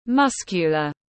Người cơ bắp tiếng anh gọi là muscular, phiên âm tiếng anh đọc là /ˈmʌs.kjə.lər/ .